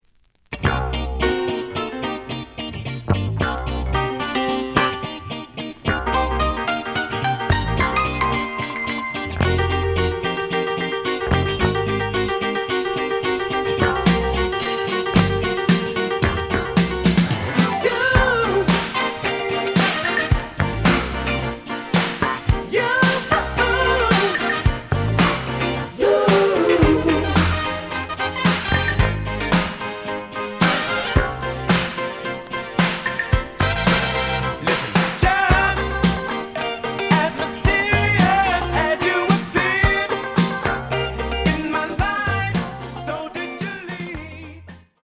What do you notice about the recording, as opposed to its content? The audio sample is recorded from the actual item. A side plays good.